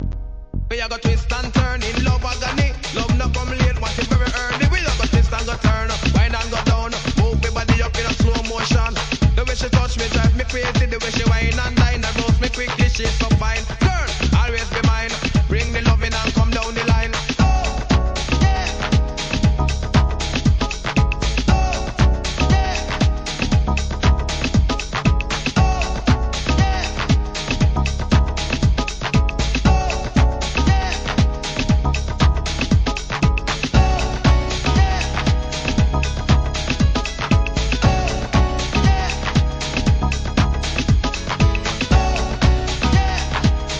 HIP HOP/R&B
ラガMCを迎えたHOUSE REMIX!!